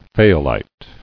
[fay·a·lite]